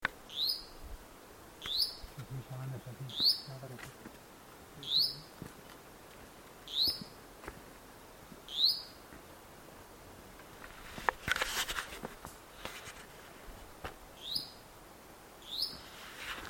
Yellow-olive Flatbill (Tolmomyias sulphurescens)
Location or protected area: Bio Reserva Karadya
Condition: Wild
Certainty: Recorded vocal